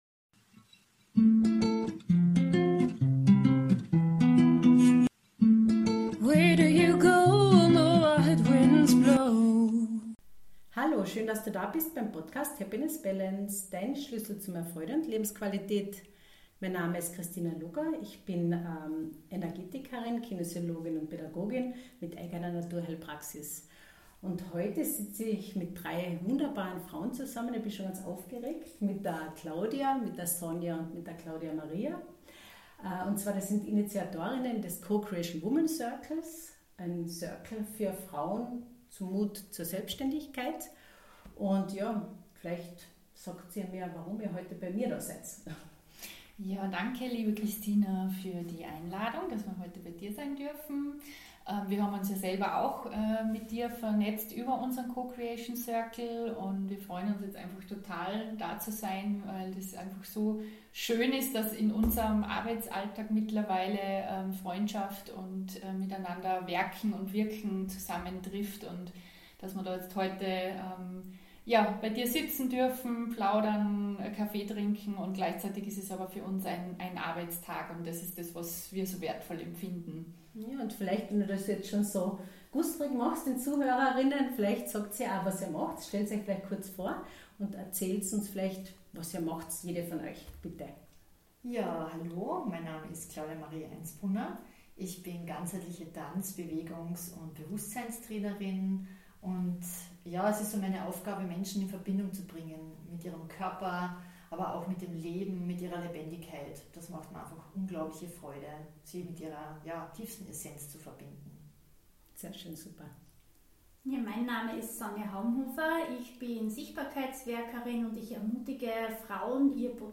Im heutigen Podcast-Interview geht es um drei Frauen, ihre drei unterschiedlichen Geschichten und eine gemeinsame Vision: Frauen auf dem Weg in die Selbständigkeit zu unterstützen.
Ein inspirierendes Gespräch voller Frauenpower, das Mut machen soll.